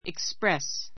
express 中 A2 iksprés イ クスプ レ ス 動詞 表現する, 言い表す He couldn't express himself correctly in English.